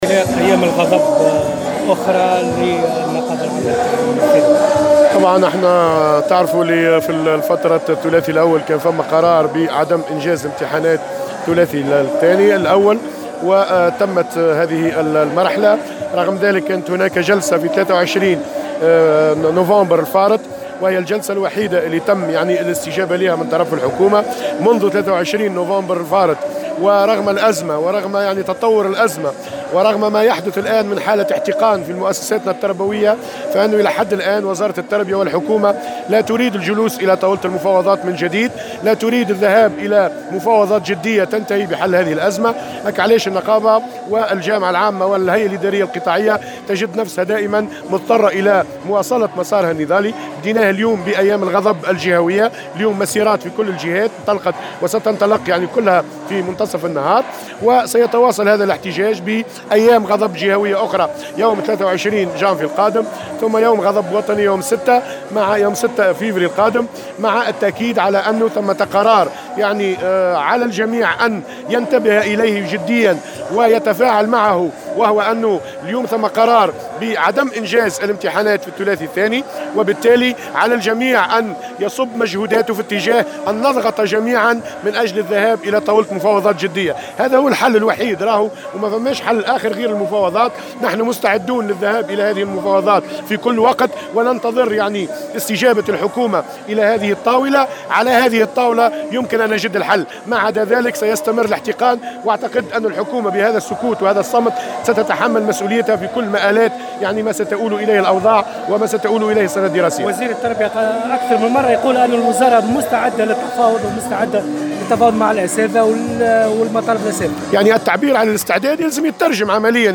وقال على هامش يوم غضب جهوي بتونس العاصمة إن تفاقم الأزمة وحالة الاحتقان التي تشهدها المؤسسات التربوية لا تدفع وزارة التربية والحكومة إلى مفاوضات جدية.